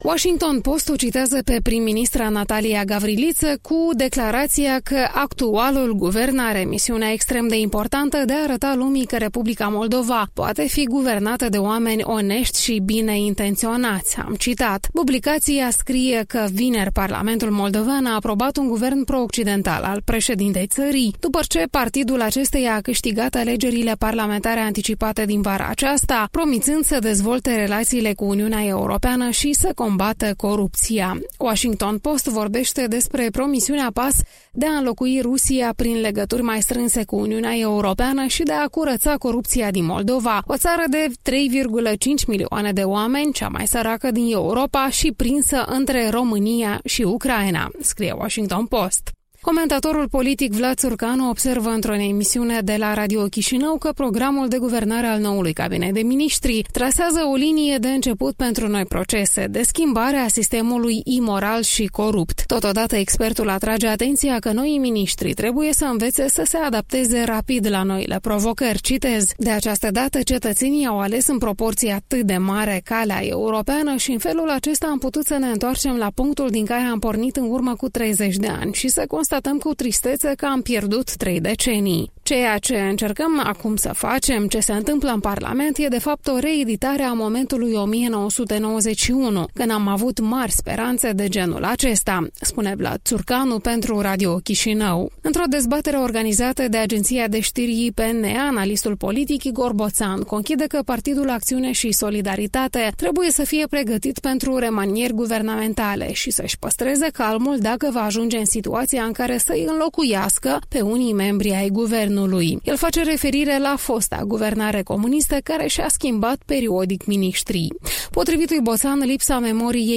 Revista presei matinale